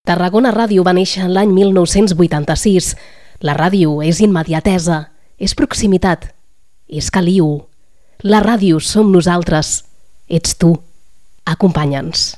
Enregistrament fet amb motiu del Dia mundial de la ràdio 2022.
FM